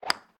Golf_Hit_Ball.ogg